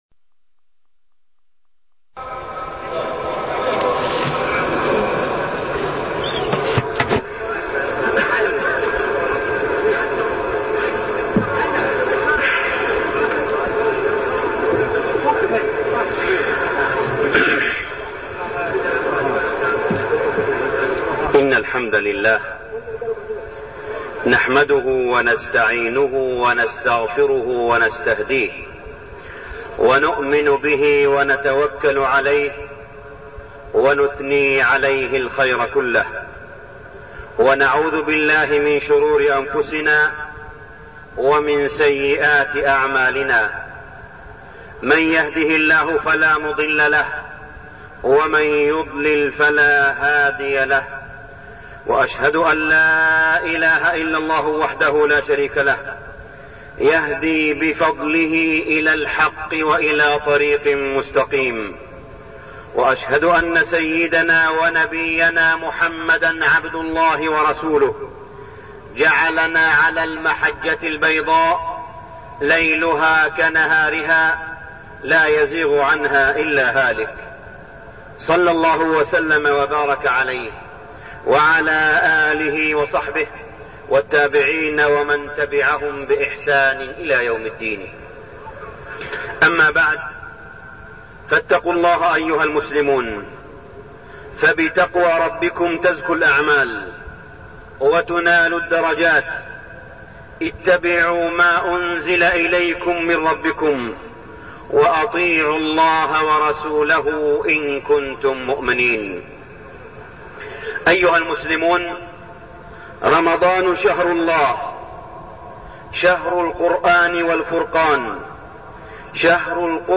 تاريخ النشر ١٩ رمضان ١٤١٣ هـ المكان: المسجد الحرام الشيخ: معالي الشيخ أ.د. صالح بن عبدالله بن حميد معالي الشيخ أ.د. صالح بن عبدالله بن حميد شهر القرآن والفرقان